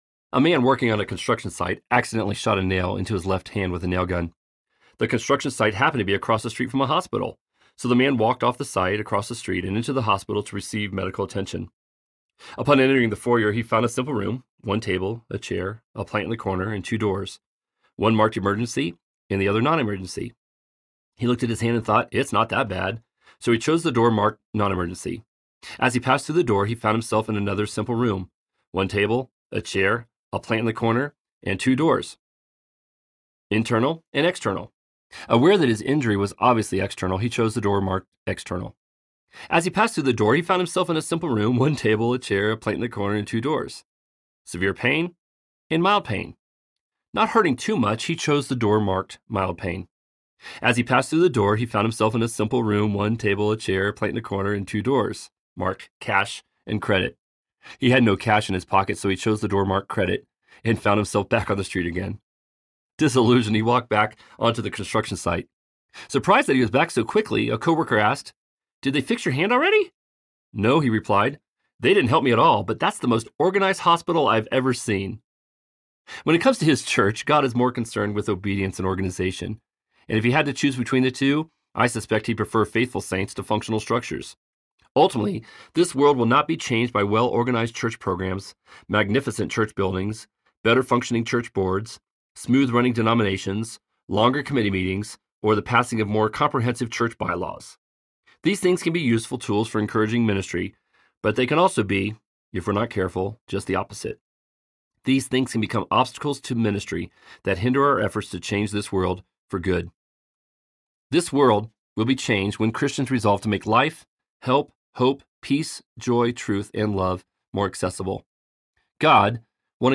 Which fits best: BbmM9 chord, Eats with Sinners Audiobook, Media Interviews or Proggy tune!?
Eats with Sinners Audiobook